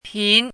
chinese-voice - 汉字语音库
pin2.mp3